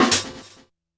snare01.wav